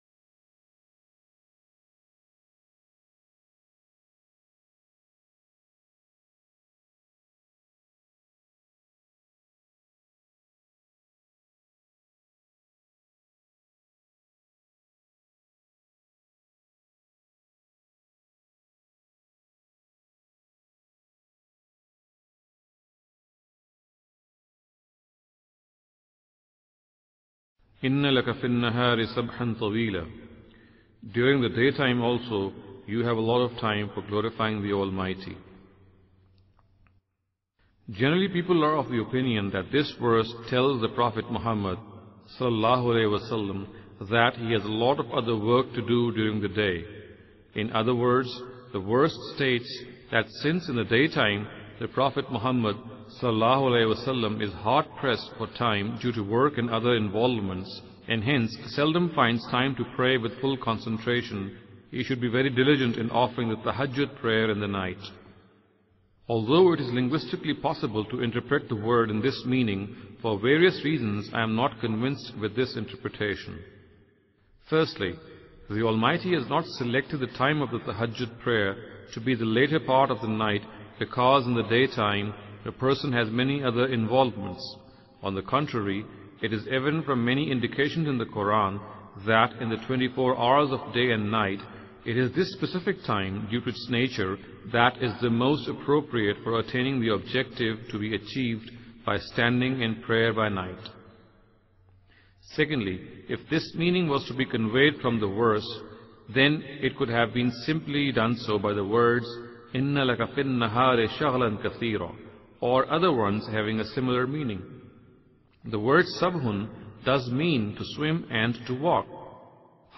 Imam Amin Ahsan Islahi's Dars-e-Qur'an.